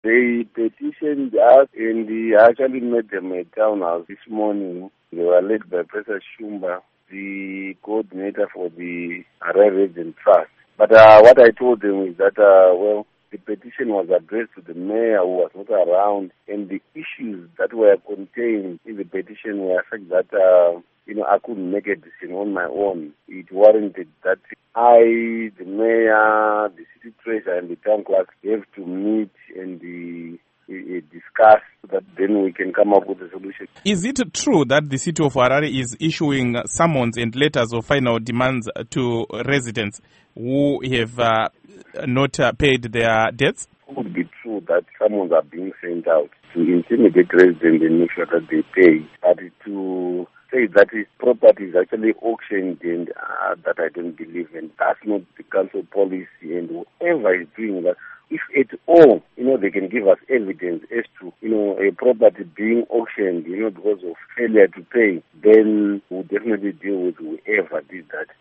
Interview With Emmanuel Chiroto